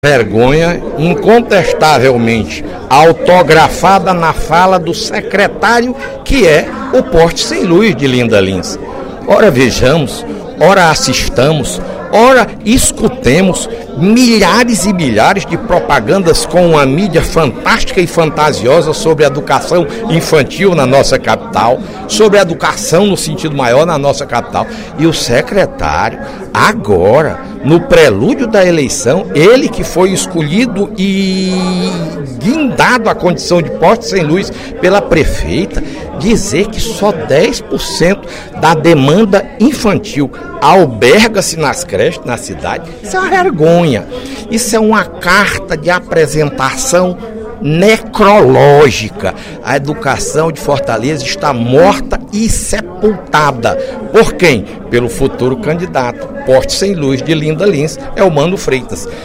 O líder do PSDB na Assembleia Legislativa, deputado Fernando Hugo, comentou, na sessão plenária desta terça-feira (10/04), declaração feita ontem pelo titular da Secretaria da Educação de Fortaleza, Elmano de Freitas. Pré-candidato do Partido dos Trabalhadores à prefeitura da Capital, ele afirmou que apenas 10% das crianças da cidade são atendidas pelas creches mantidas pela gestão municipal.